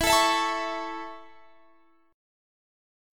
Listen to E6sus strummed